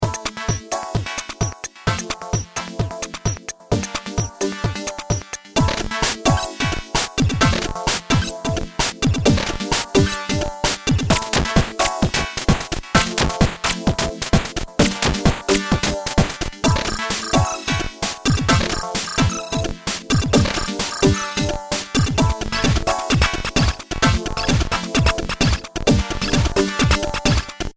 (VoidBred) Home | Blog | Releases | About | Contact 8bit Drummer v1.1.0 Description: 8bit Drummer is a drum machine loaded with video game sounds for the mac.
(drums done with 8bit Drummer completely, there is just an accompanying synth)
8bitDemo.mp3